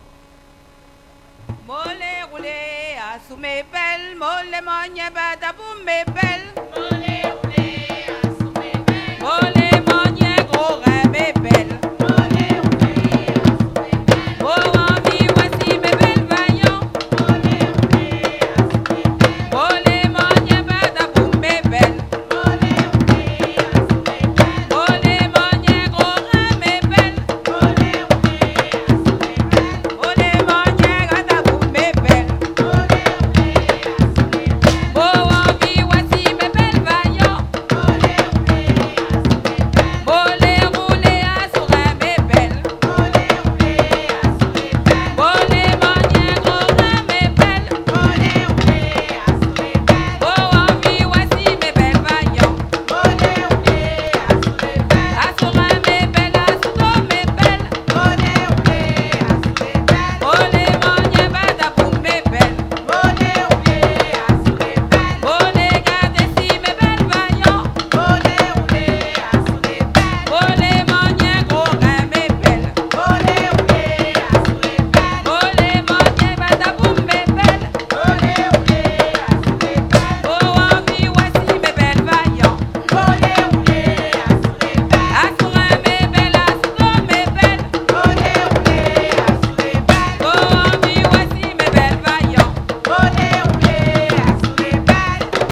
Saint-Georges-de-l'Oyapoc
danse : débò (créole)
Pièce musicale inédite